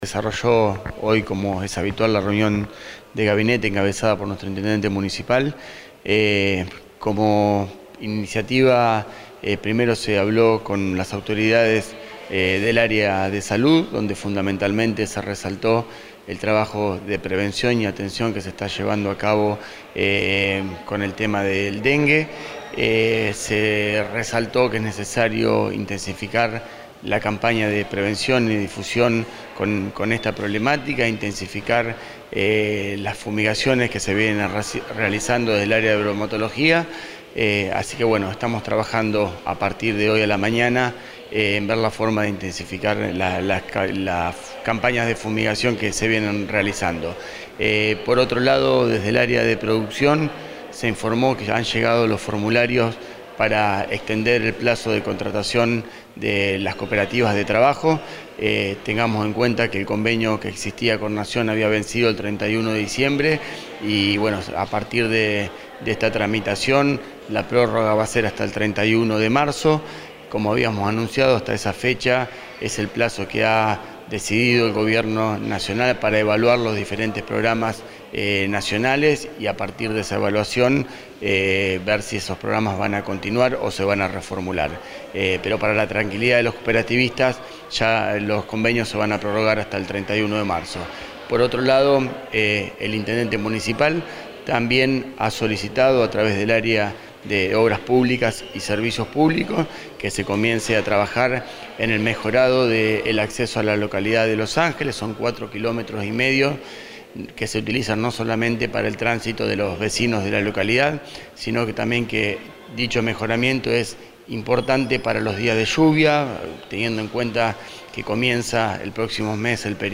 1/2/16- Esta mañana, luego de la reunión habitual de gabinete en el Palacio Municipal, el secretario de Gobierno y Hacienda, Mariano Cámera, comunicó en conferencia de prensa que se resaltó el trabajo de prevención y atención que se está llevando a cabo respecto a la problemática del dengue.
MARIANO-CAMERA-SEC.-DE-GOBIERNO-CONF.-DE-PRENSA-REUNION-DE-GABINETE.mp3